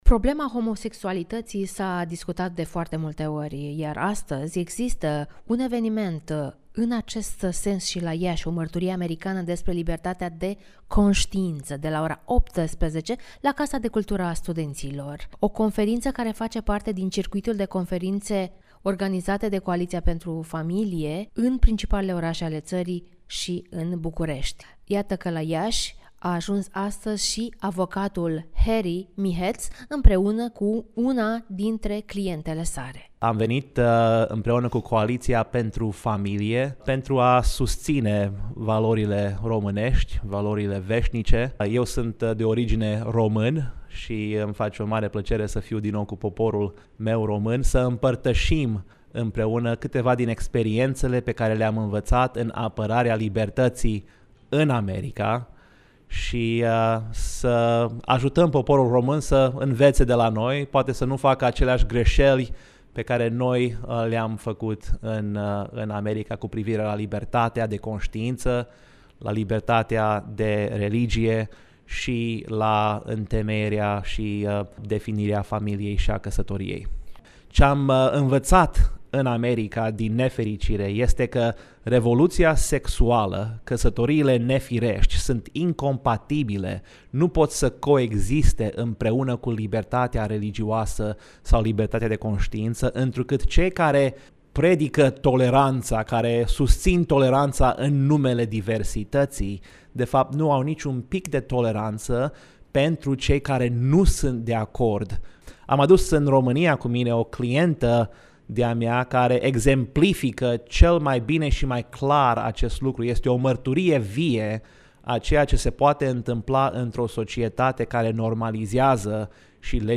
Un reportaj despre libertatea de conştiinţă